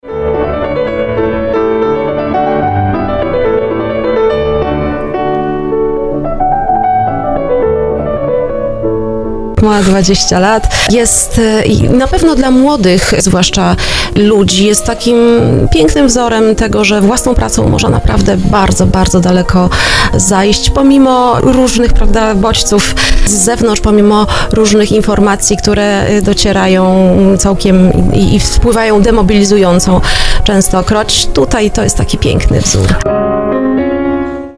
mówiła na antenie RDN Małopolska